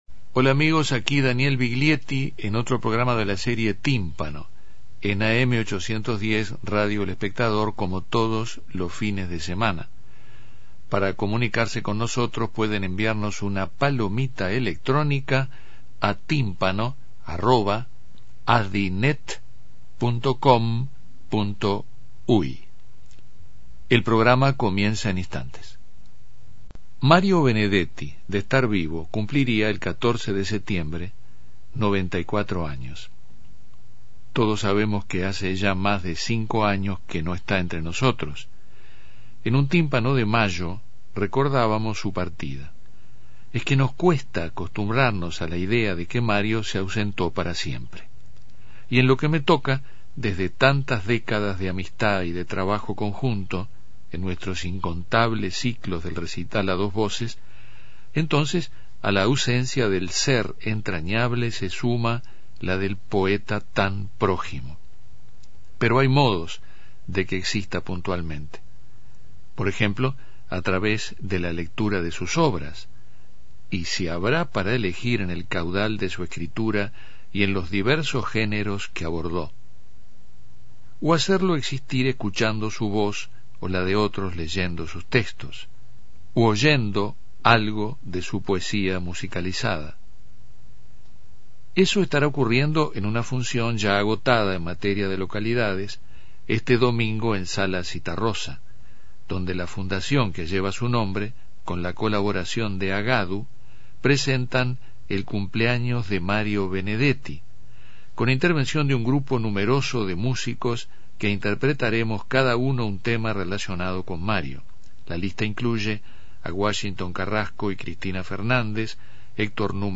En este programa Daniel Viglietti abre el tímpano a jóvenes músicos que han compuesto e interpretan canciones sobre poemas de nuestro gran escritor, que habría cumplido, de estar vivo, 94 años el 14 de setiembre.